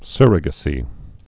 (sûrə-gə-sē, sŭr-)